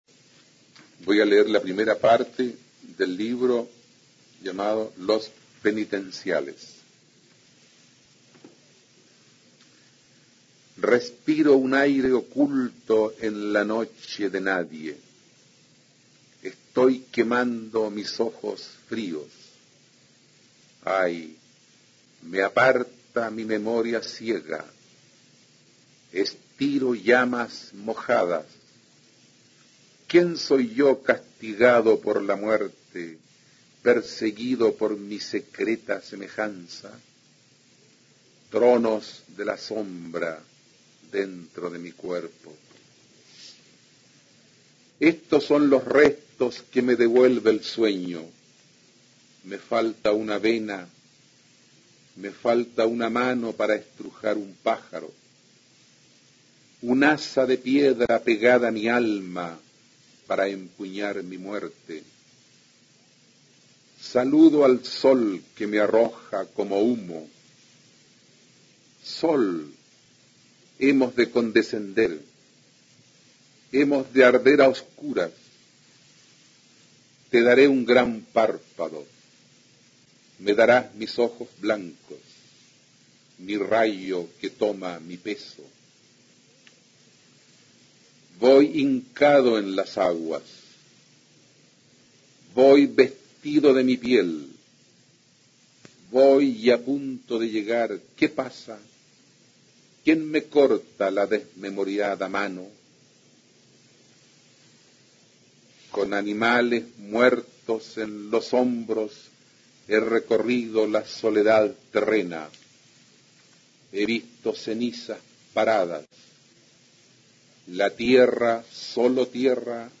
A continuación se puede escuchar a Humberto Díaz-Casanueva, destacado autor de las vanguardias hispanoamericanas y Premio Nacional de Literatura en 1971, leyendo la primera parte de su libro "Los penitenciales" (1960).
Poesía